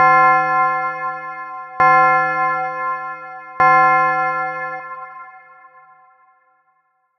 《ウエディングベル》フリー効果音
３回鳴るチャペル・教会の鐘の効果音。結婚式で3回鳴らすと縁起が良いらしい。
wedding-bell.mp3